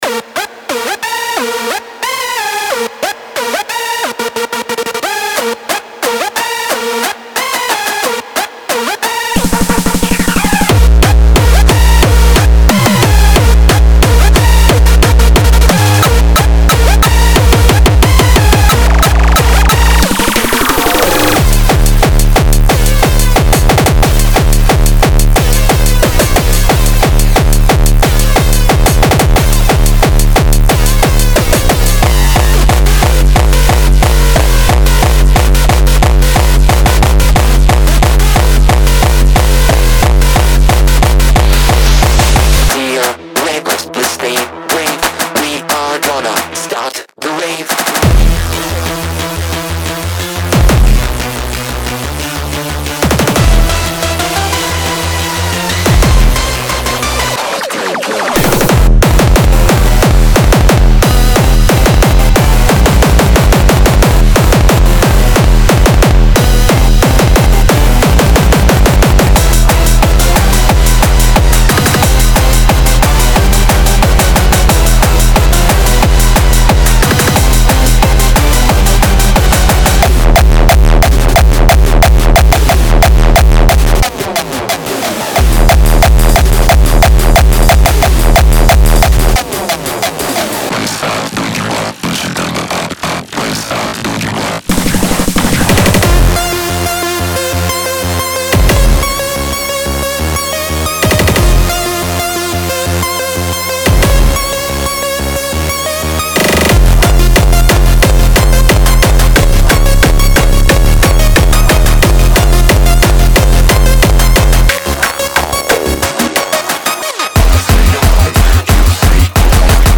Genre:Hardcore
ハードコア、アップテンポ、ガバ、インダストリアルなどのスタイルに最適で、
180 BPM
20 Drum Loops
42 Synth Loops